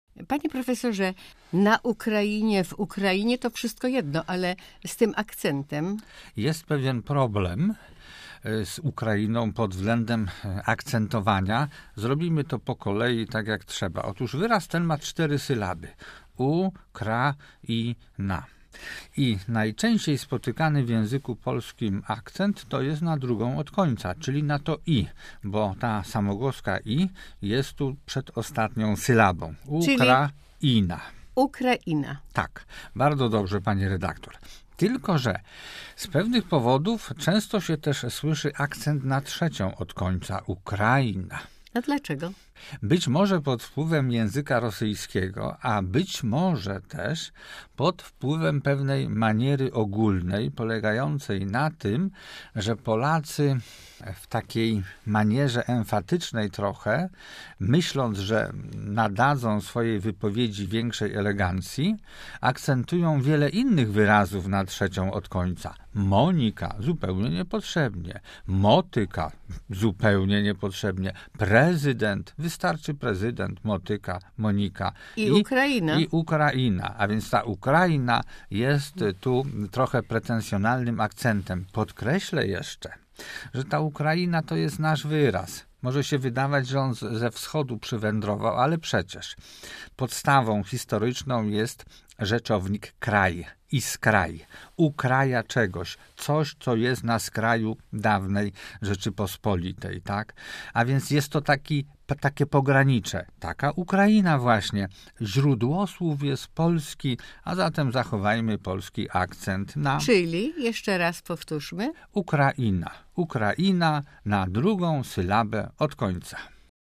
Akcent na drugiej od końca sylabie, nie na pierwszej!